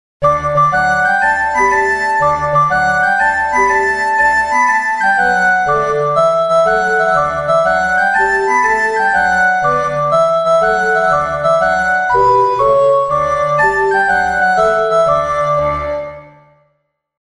Just like the last one, but with two recorders playing an octave apart. Playing tight chords on this can make it sound acceptably like an harmonium. Slacker chords can make it sound unacceptably like a penguin having an enema.
octaverecorders.mp3